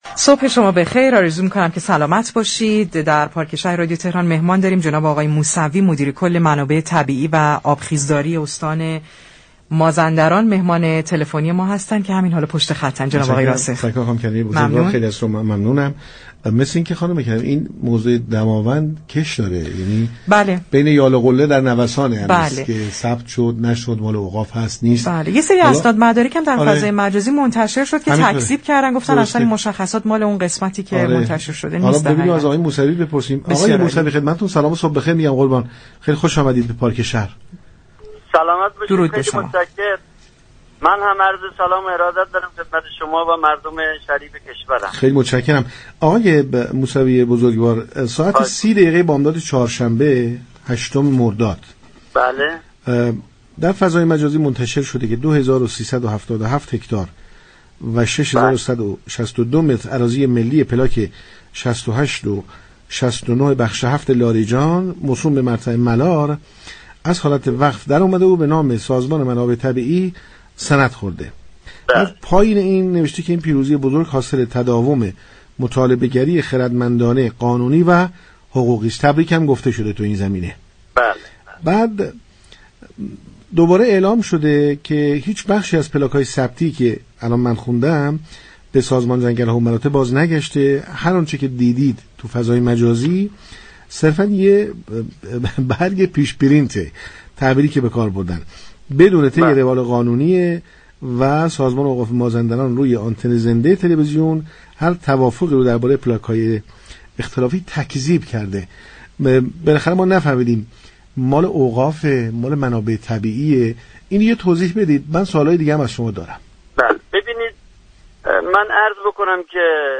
مهندس سید محسن موسوی تاكامی ، مدیركل منابع طبیعی و آبخیزداری استان مازندران در رابطه با پلاك های 68 و 69 دامنه های دماوند و وقف جنگل های هیركانی با پارك شهر رادیو تهران گفتگو كرد.